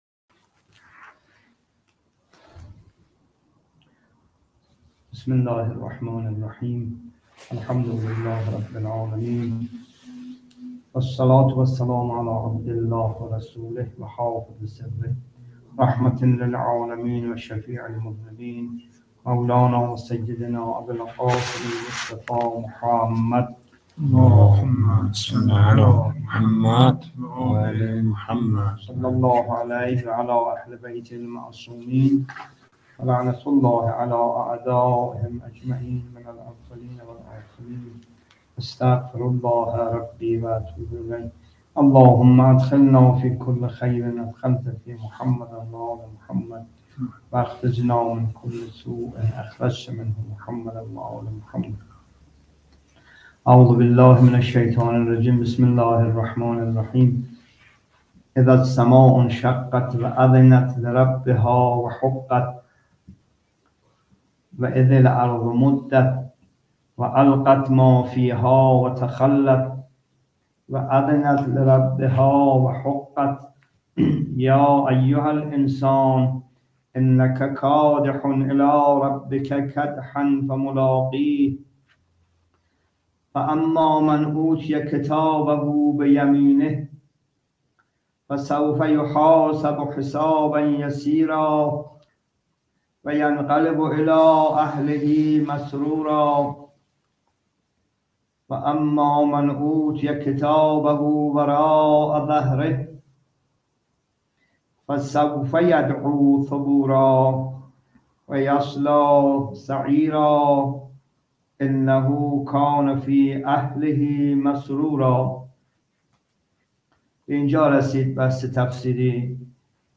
جلسه تفسیر قرآن (۱۱) سوره انشقاق